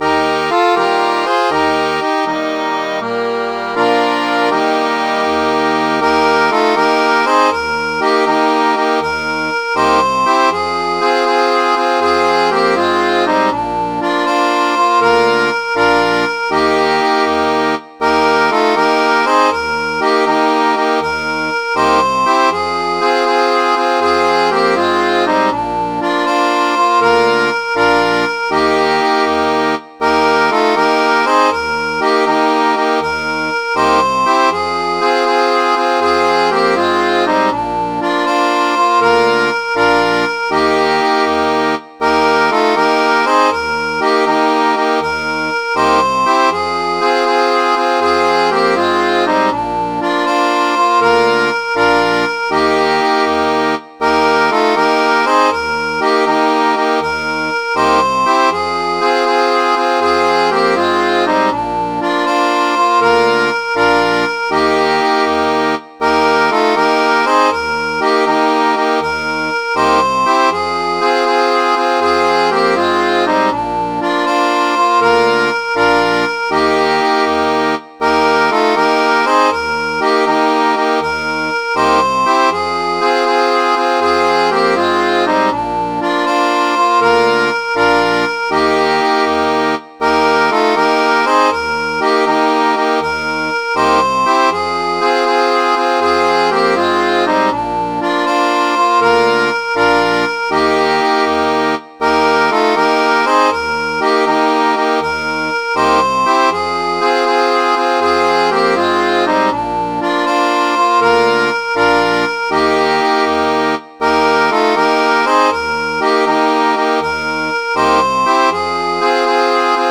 Midi File, Lyrics and Information to Boney Was A Warrior